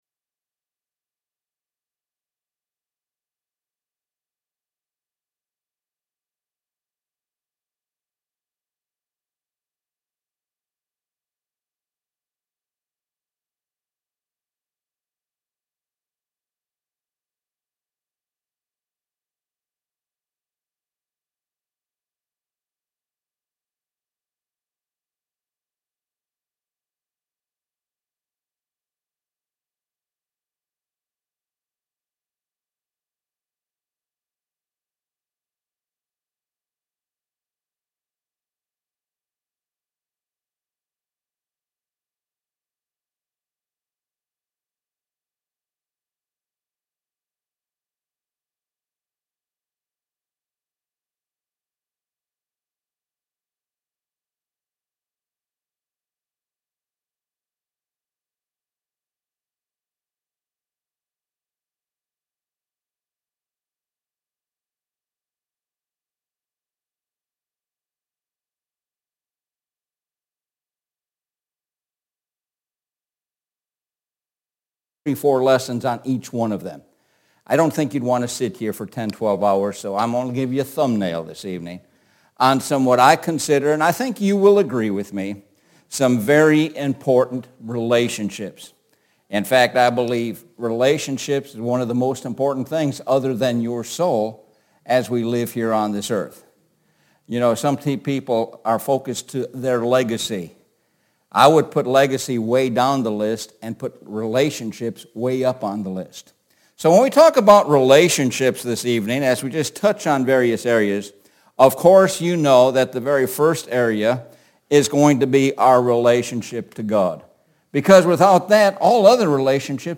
Lakeland Hills Blvd Church of Christ